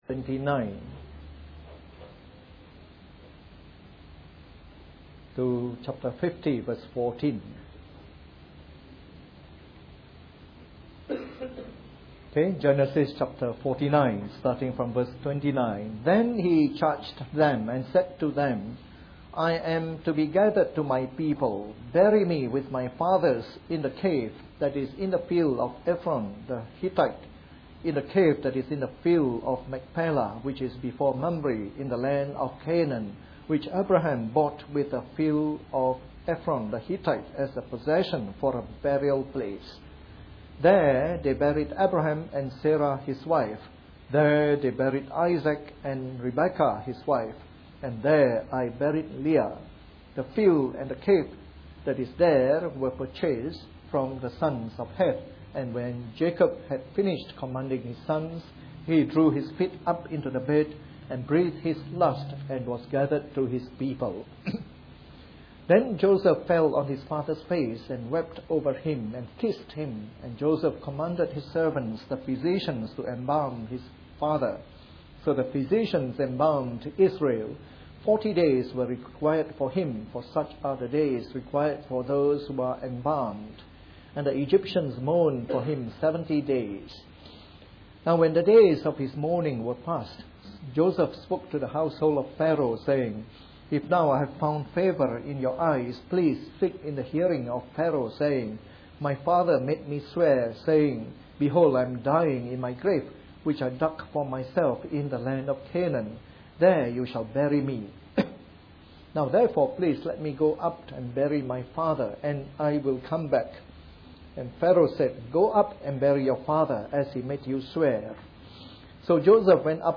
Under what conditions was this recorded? Part of our series on the Book of Genesis delivered in the Morning Service.